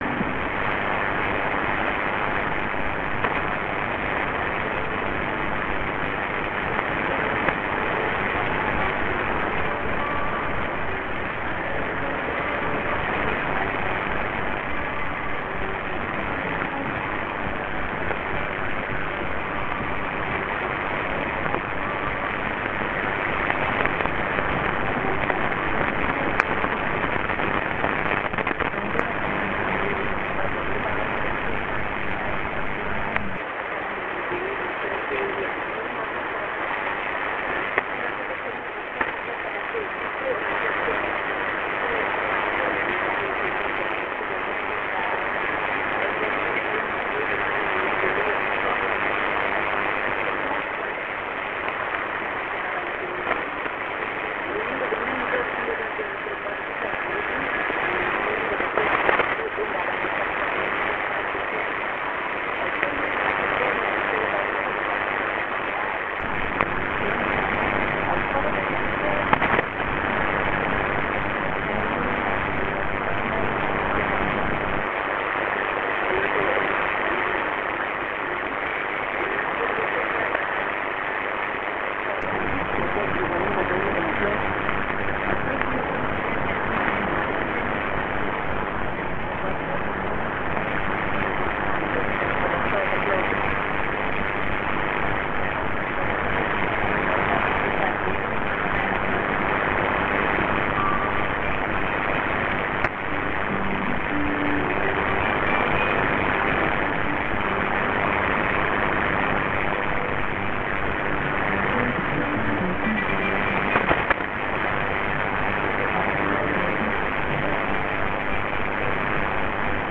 Come tanti altri dxers italiani anch'io non ascoltavo più ZBC Radio Three su 3396 kHz, dallo Zimbabwe, da una decina d'anni. Ora è stata riattivata e arriva discretamente anche qui in Sicilia dopo il tramonto.
clip> [per questo e tutti gli altri proposti usare sempre le cuffie, è meglio, dato il livello di rumore di alcune registrazioni] intorno alle 1835 utc di oggi.